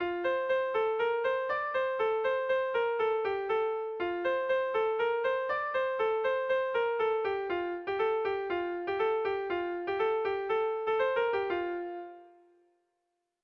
Haurrentzakoa
Lauko ertaina (hg) / Bi puntuko ertaina (ip)
A1A2